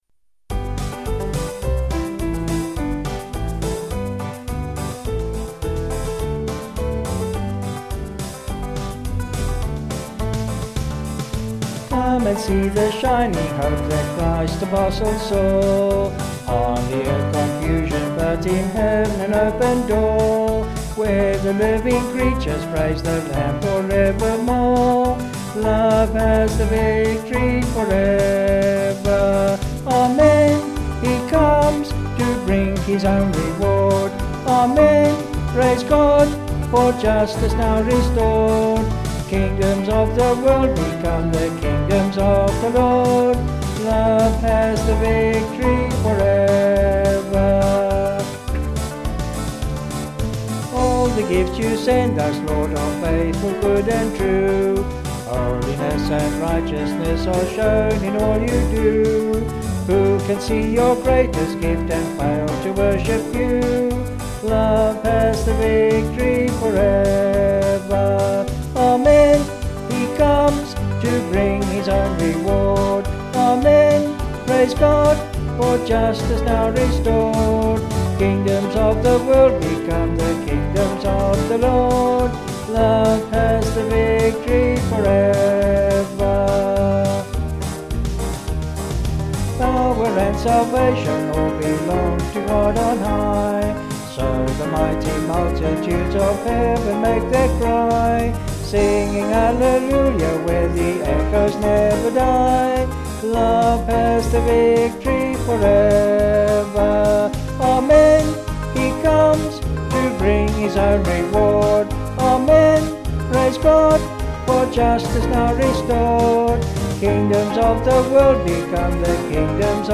Vocals and Band   263.6kb